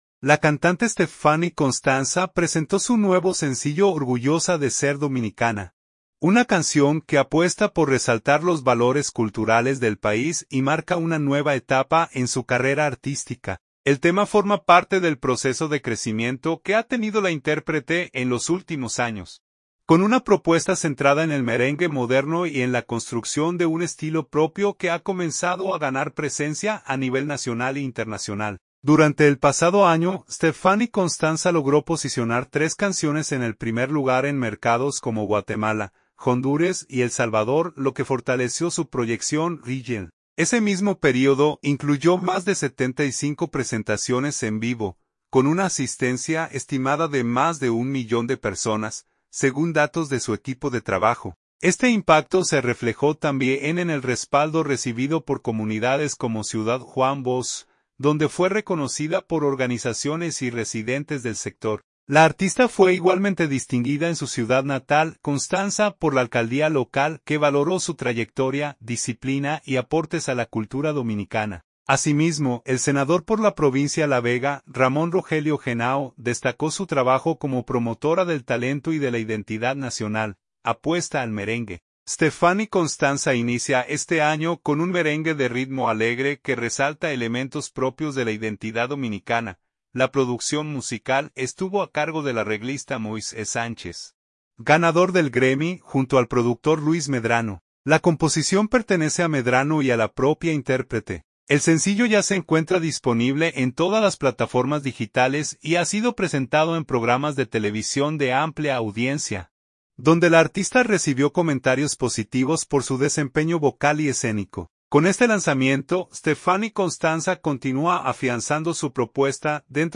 merengue de ritmo alegre
merengue contemporáneo